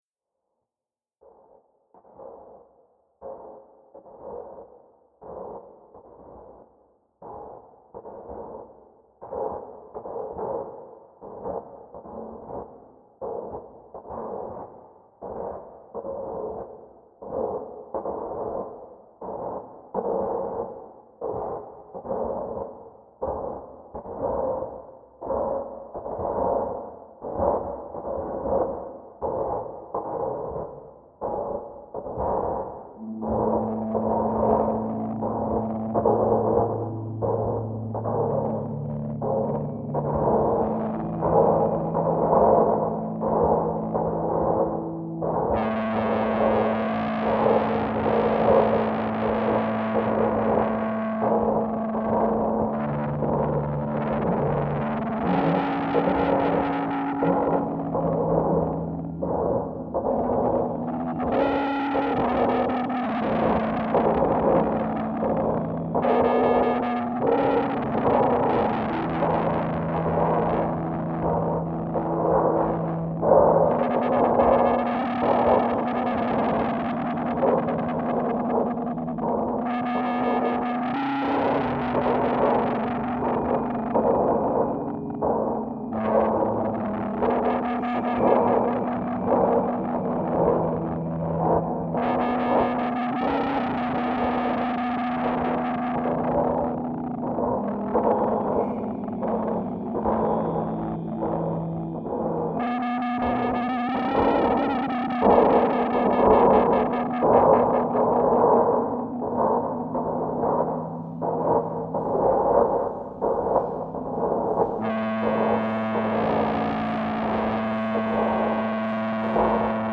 Nine Emperor Gods Festival sounds reimagined